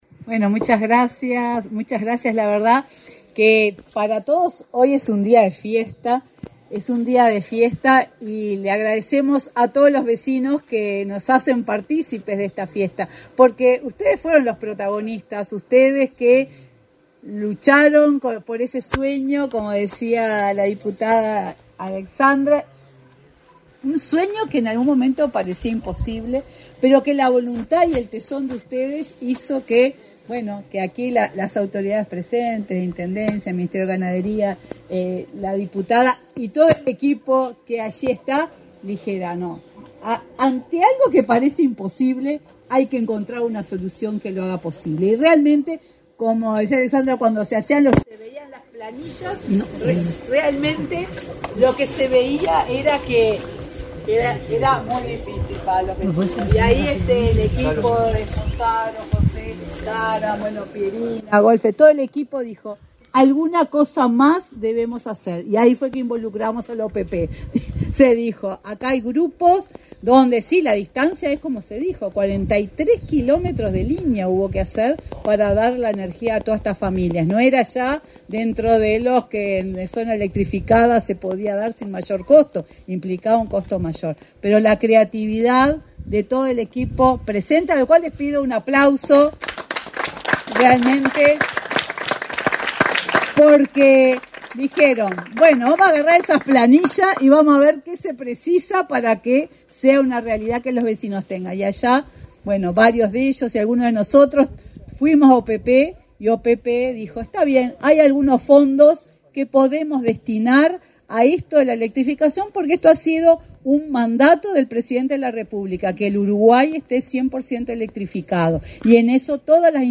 Palabras de la presidenta de UTE, Silvia Emaldi
Palabras de la presidenta de UTE, Silvia Emaldi 20/12/2024 Compartir Facebook X Copiar enlace WhatsApp LinkedIn UTE inauguró, este 20 de diciembre, obras de electrificación rural en El Barrancón, departamentó de Lavalleja, donde 33 familias accederán a la red de energía eléctrica. En la oportunidad, disertó la presidenta de la empresa estatal, Silvia Emaldi.